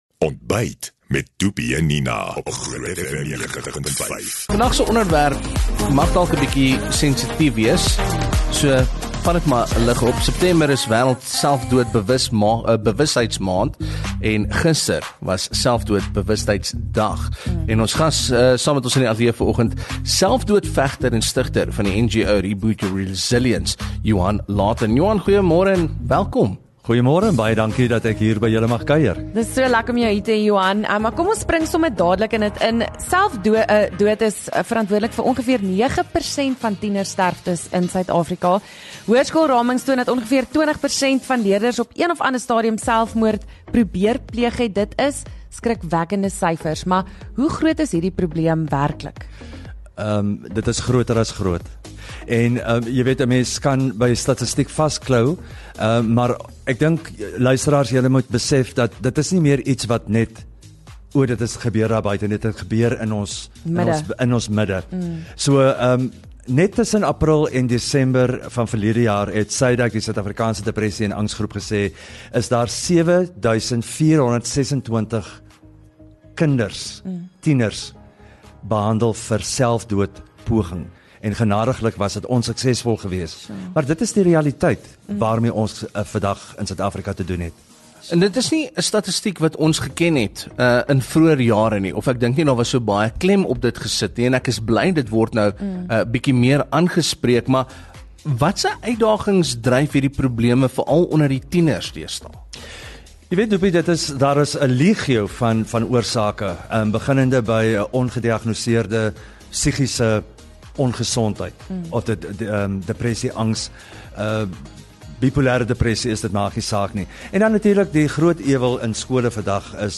September is wereld selfdood-bewustheidsmaand, gister was selfdood bewustheidsdag, en dit is so ‘n belangrike onderwerp dat ons net daaroor MOES gesels. By ons in die ateljee was n selfdoodvegter